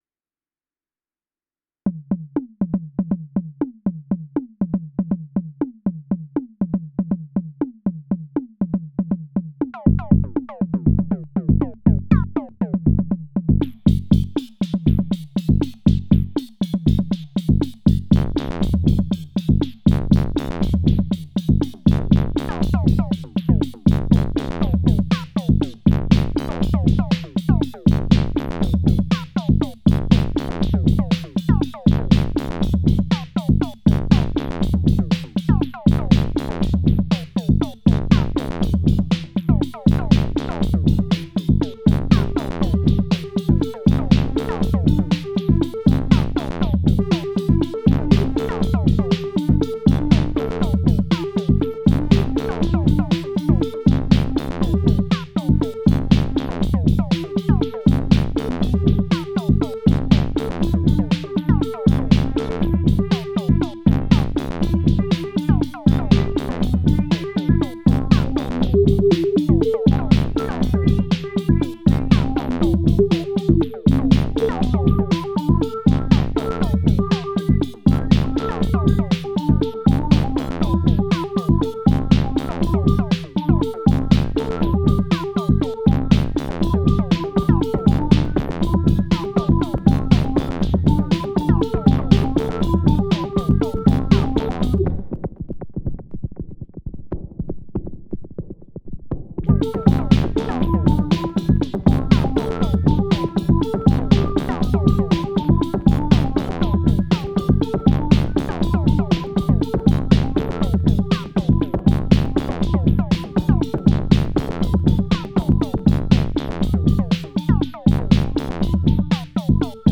:grin: (Or I may have just ended up with the same Syntakt sound.)